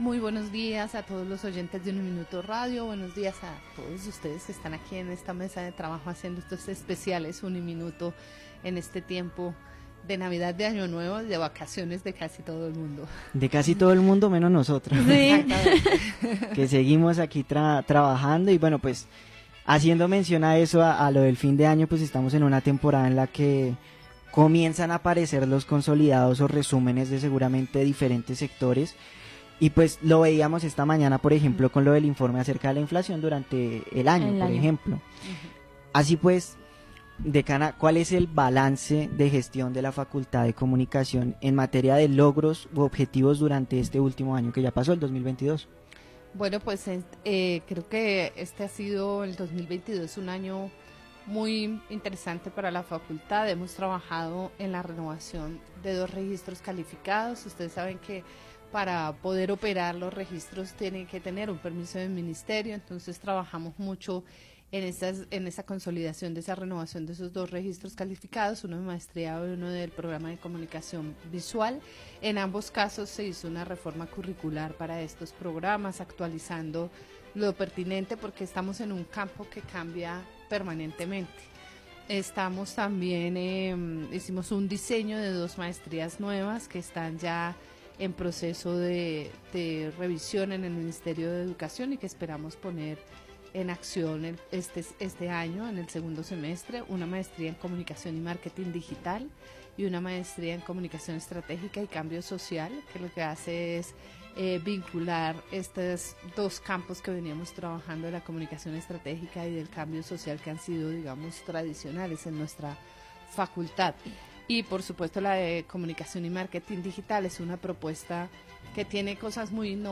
entrevista-decana.mp3